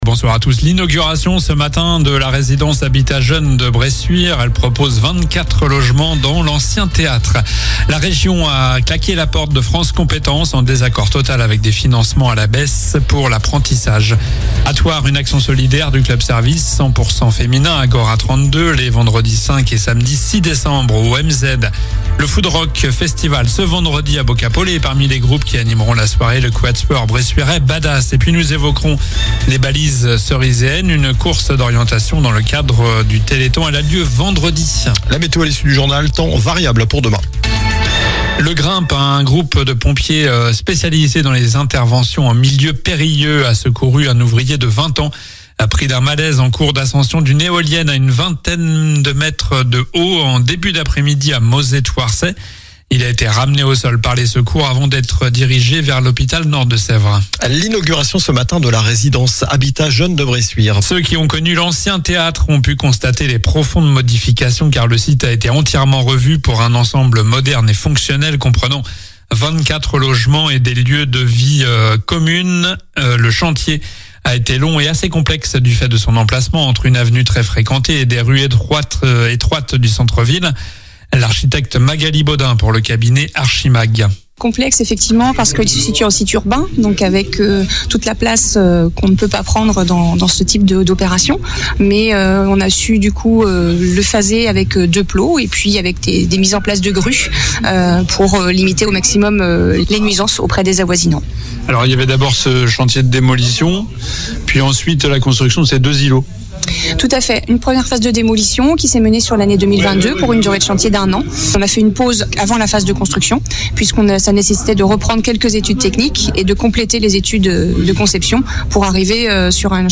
Journal du mercredi 3 décembre (soir)